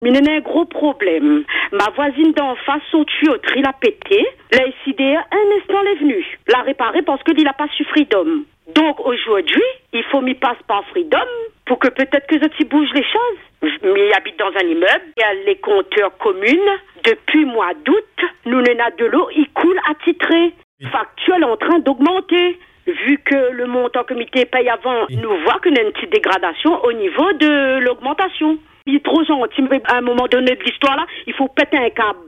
Vous allez l’entendre : elle a décidé de suivre le même chemin… et c’est pour cette raison qu’elle nous appelle aujourd’hui.